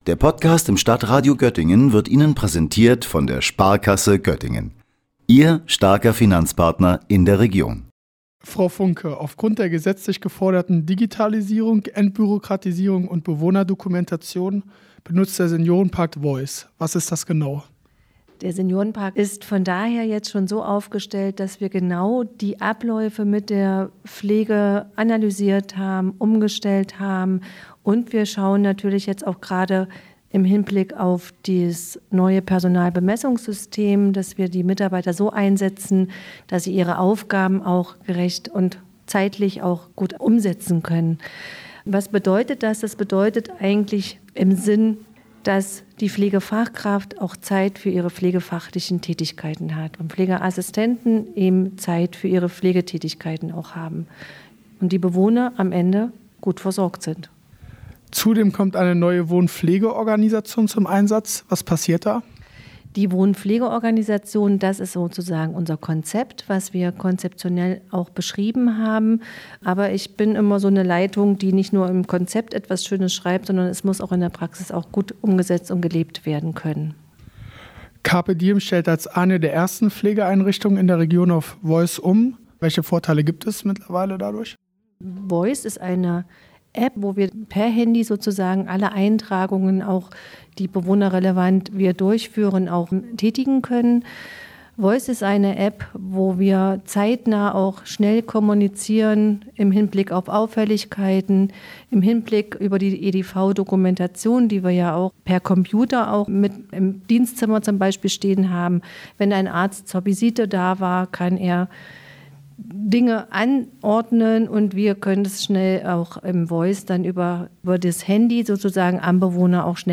Der Senioren-Park „Carpe Diem“ nutzt mittlerweile Smartphone-Apps in der Pflege. Dabei kommen die Apps „Voize“ (Gesprochen wie die Stimme) und „Myo“ (Gesprochen Mio) zum Einsatz.